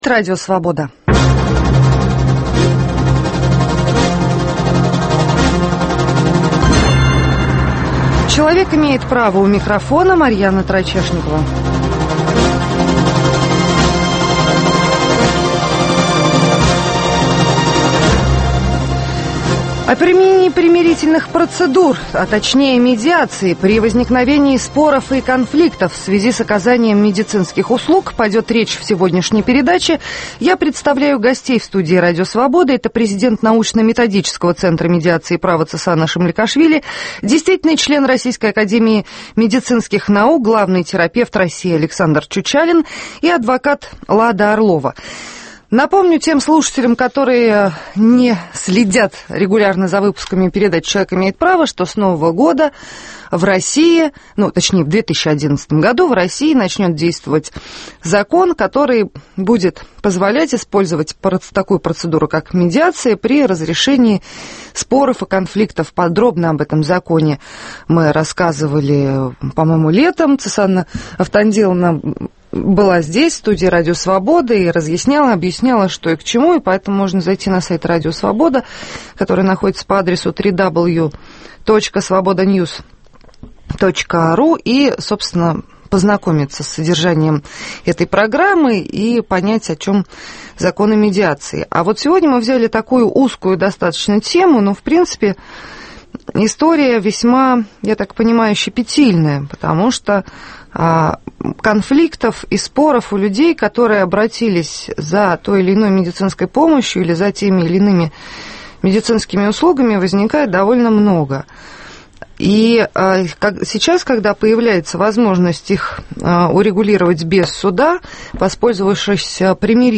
О правах и свободах говорят правозащитники, политики, экономисты, деятели науки и культуры, обеспокоенные состоянием дел с правами. О применении примирительных процедур (медиации) при возникновении споров и конфликтов в связи с оказанием медицинских услуг. Чем примирительные процедуры отличаются от мирового соглашения, заключенного в связи с недовольством пациентов качеством медицинского обслуживания?